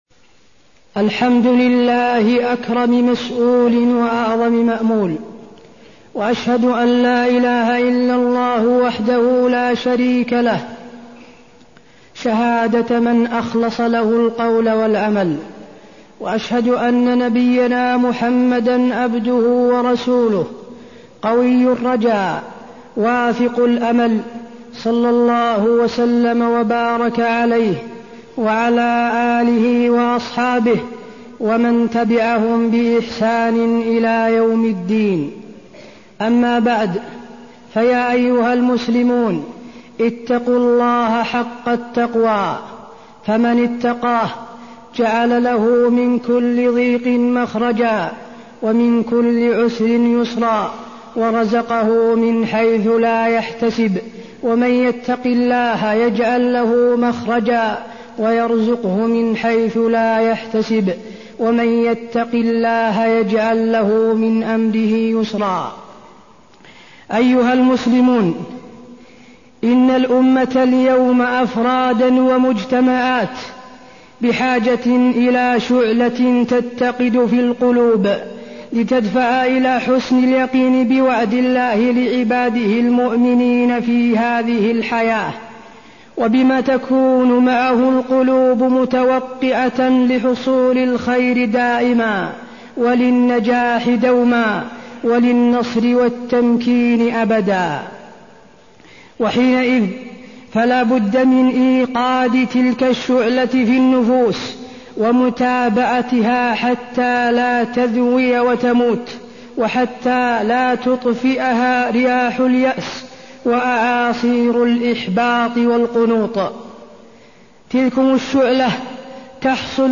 خطبة الأمل واليأس وفيها: حاجة الأمة إلى بث شعلة الأمل فيها، تعرف على ثمرات الأمل على النفس، وأثر اليأس على النفوس
تاريخ النشر ١٩ رمضان ١٤١٩ المكان: المسجد النبوي الشيخ: فضيلة الشيخ د. حسين بن عبدالعزيز آل الشيخ فضيلة الشيخ د. حسين بن عبدالعزيز آل الشيخ الأمل واليأس